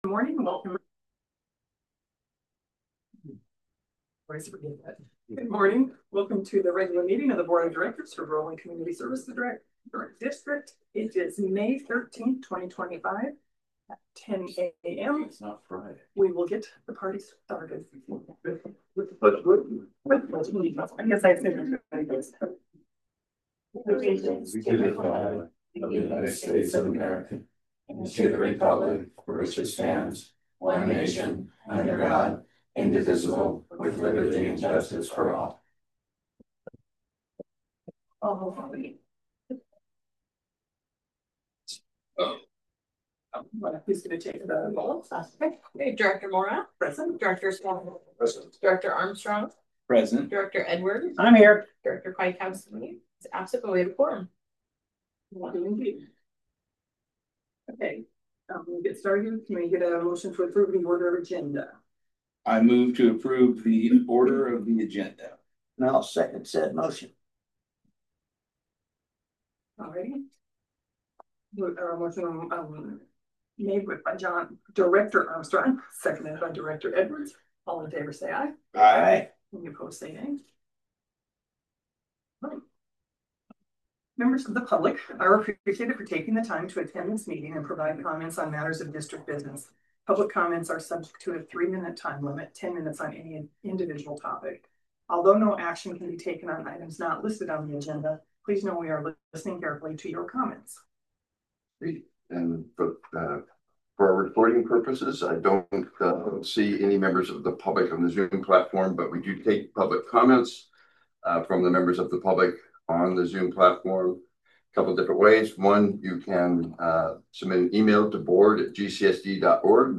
Regular Meetings are scheduled on the second Wednesday of each month at 9am in the board room located at the Groveland Community Resilience Center…
Board Meeting